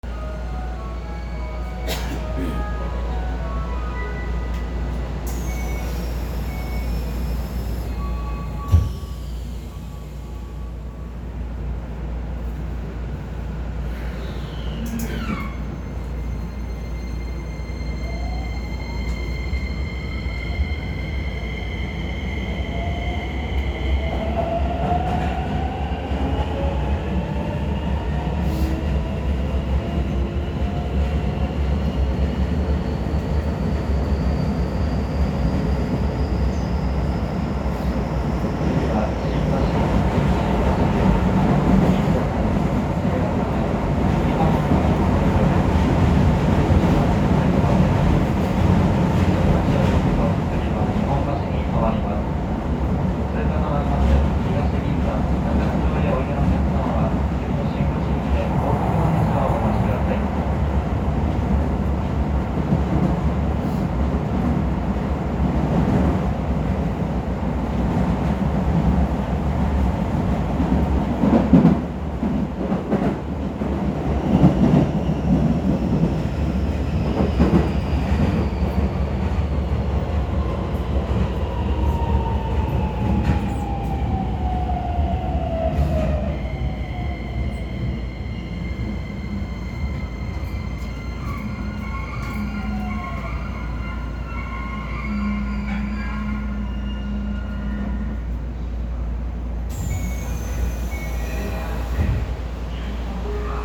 ・3100形走行音
世にも珍しい東洋SiCとなります。…が、東洋IGBTと音の聞こえ方はほぼ変わらず、新鮮味は全くありません。